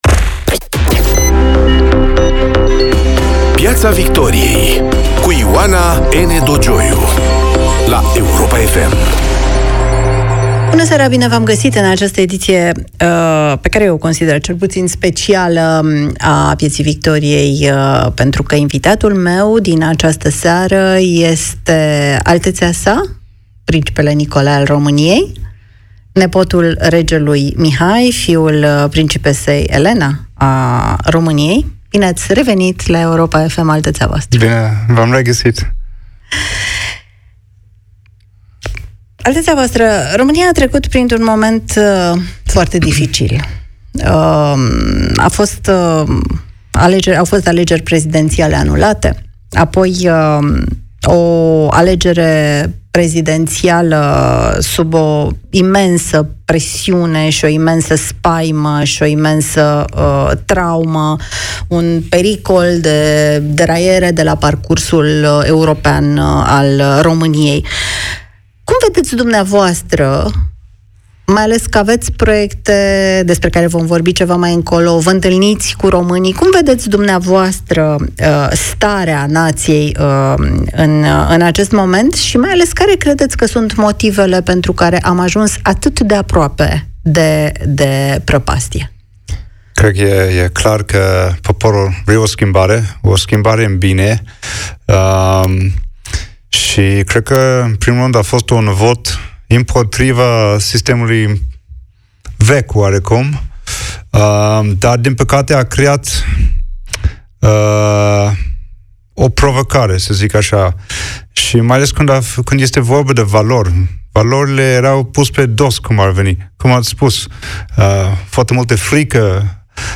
Ioana Ene Dogioiu vorbește cu invitatul său, rectorul SNSPA Remus Pricopie, despre extremism – AUR și Șoșoacă, despre comunicarea publică „suspectul de serviciu”, presa subvenționată, dar și despre ce așteptări avem de la clasa politică în 2024.